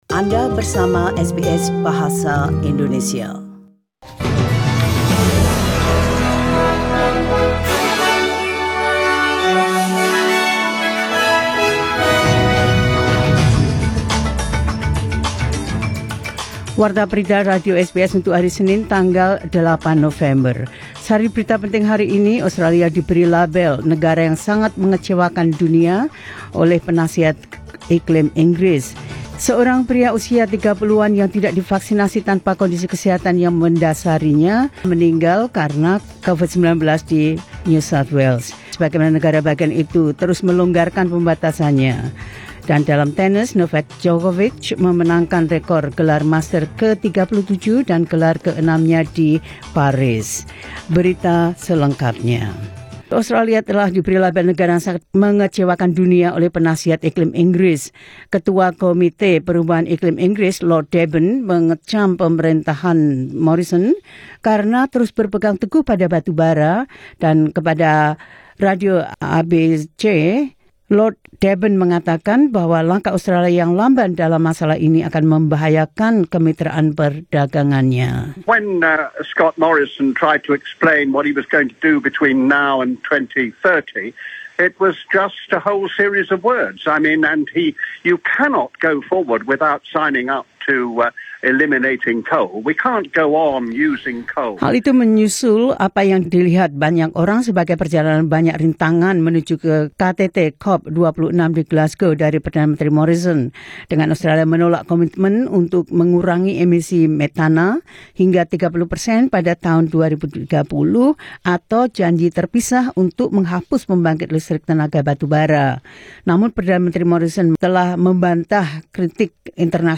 Warta Berita Radio SBS Program Bahasa Indonesia – 08 Nov 2021